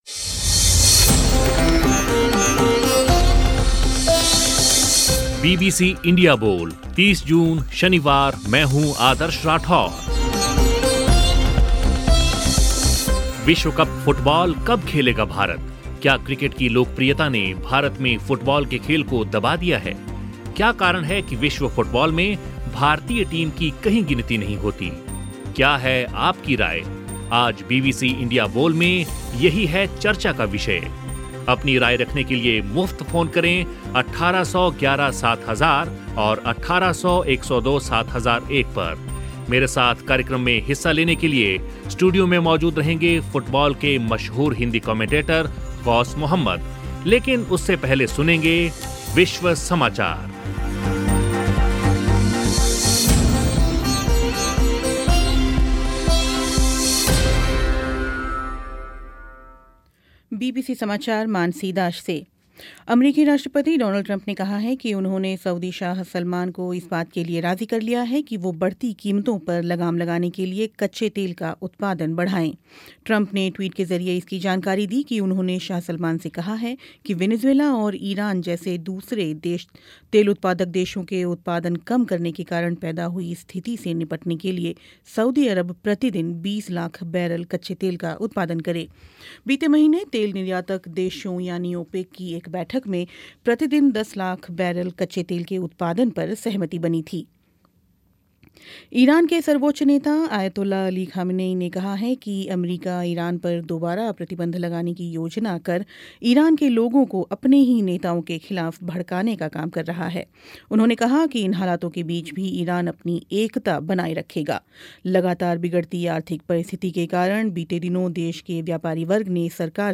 कार्यक्रम में श्रोताओं के साथ चर्चा